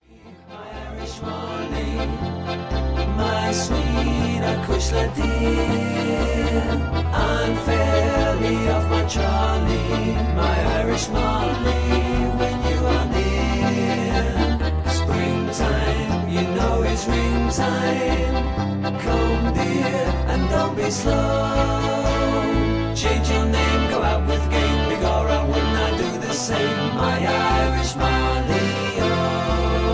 (Key-F)
Tags: backing tracks , irish songs , karaoke , sound tracks